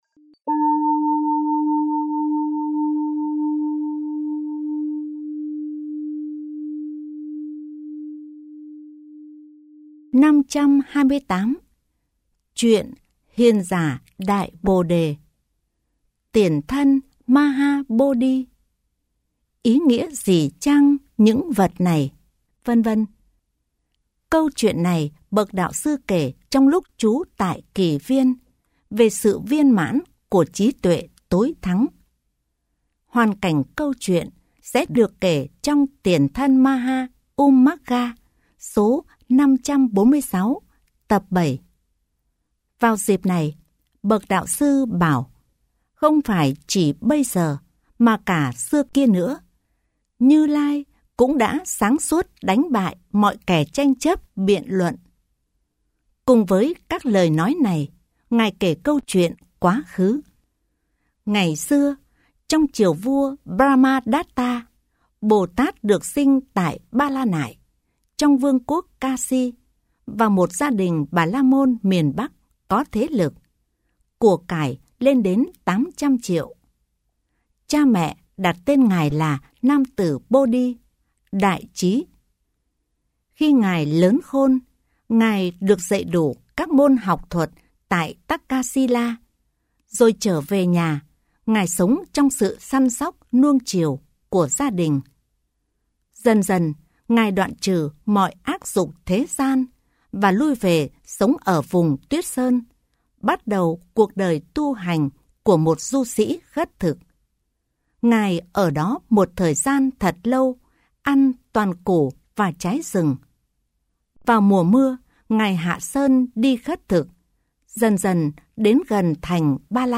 Kinh Tieu Bo 5 - Giong Mien Bac